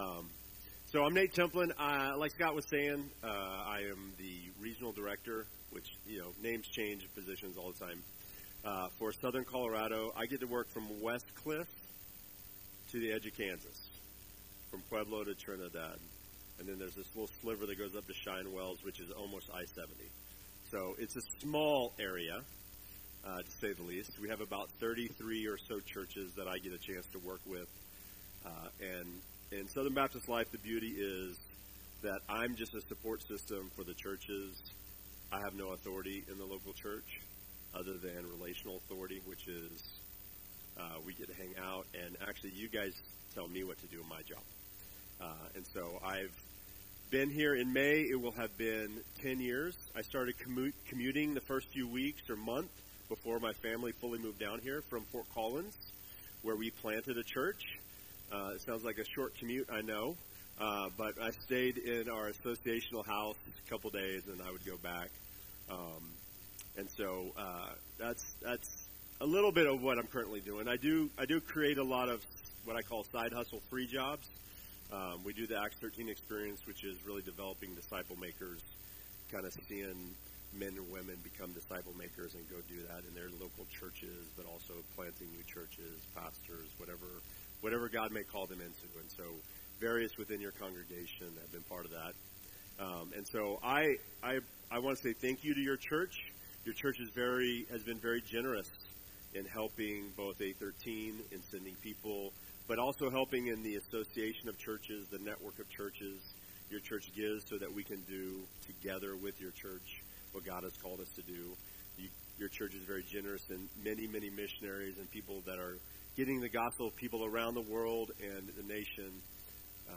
Majestic Baptist Church Sermon Series - Guest Speakers